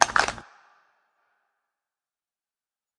Gun FX " Bersa Draw 1
描述：一支Bersa 22lr手枪正从枪套中拔出，
Tag: 火器 22LR 手枪 Bersa